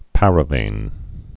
(părə-vān)